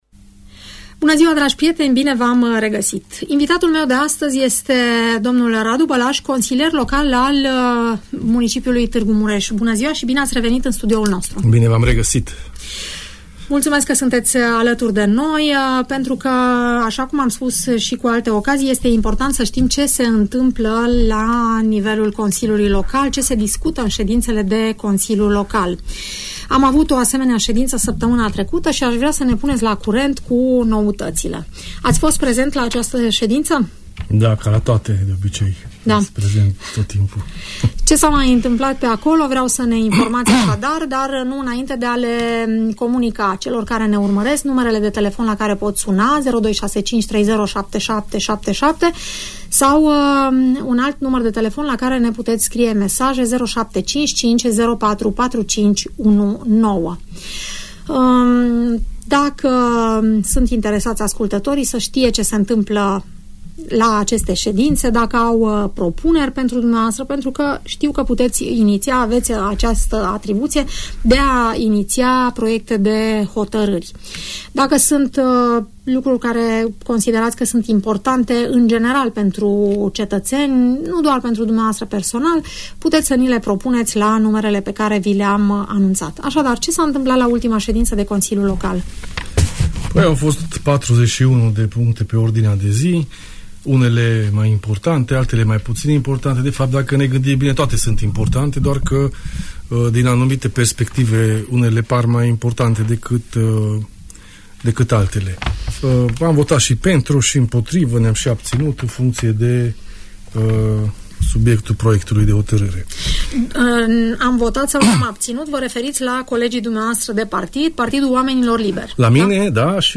Radu Bălaș, consilier local al municipiului Tg Mureș din partea Partidului Oamenilor Liberi, este invitatul emisiunii „Părerea ta”, de la Radio Tg Mureș.